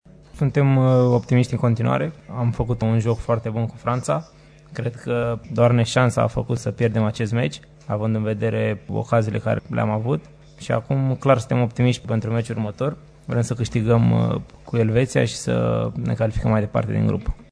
Optimismul din tabăra tricoloră e exprimat și de mijlocașul Alexandru Chipciu: